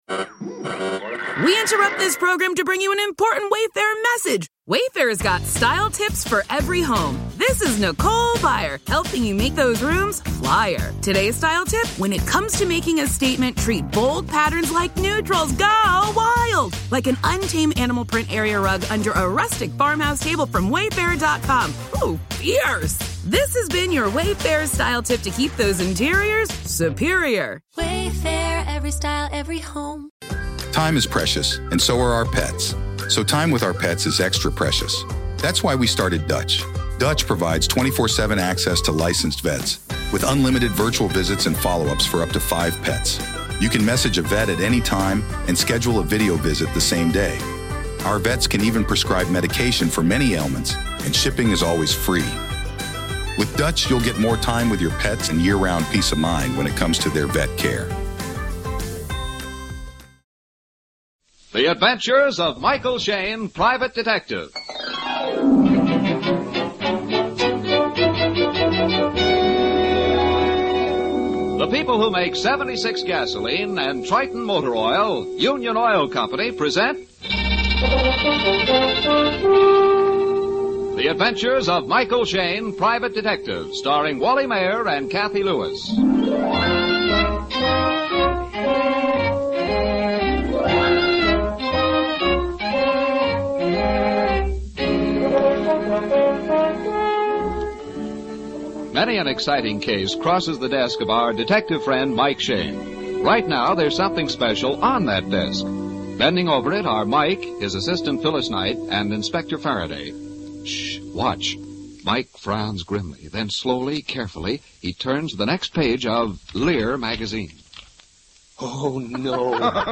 Michael Shayne 450528 Re Create A Murder, Old Time Radio